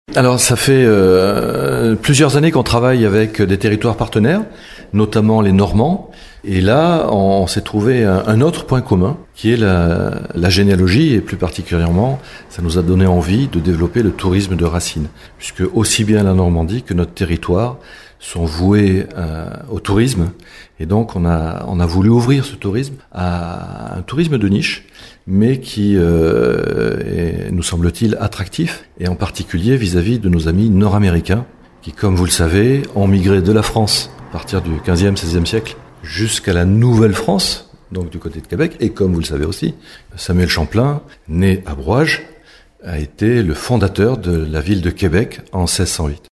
Un projet profondément lié aux racines du territoire, comme le souligne Alain Bompard, vice-président de la CdC du Bassin de Marennes, en charge de la coopération interterritoriale :